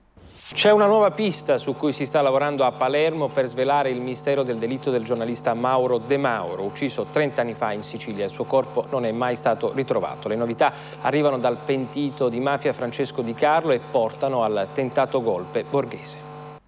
Nel 1994 l’inchiesta viene riaperta e gli incartamenti arrivano a Palermo. Il telegiornale si apre così:
telegiornale.mp3